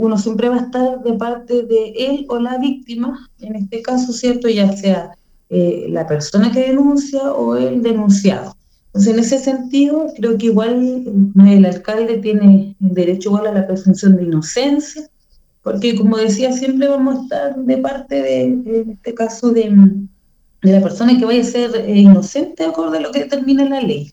Lo importante es estar de parte de la persona que será inocente, dijo la concejal del Partido Social Cristiano, Estrella Quidel.